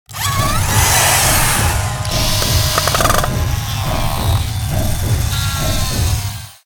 factoryrepair.ogg